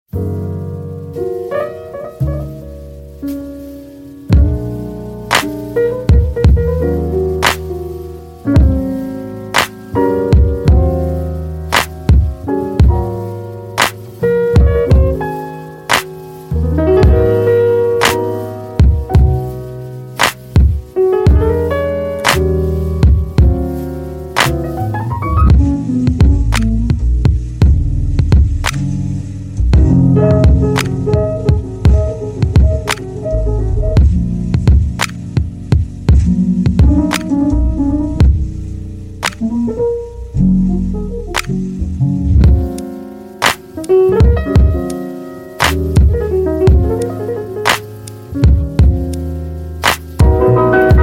Dans focus définition, tous les épisodes commencent par les publicités afin que rien ne vienne troubler ton moment d’écoute, parce que tu mérites un espace qui respire, un espace où même le silence devient un allié.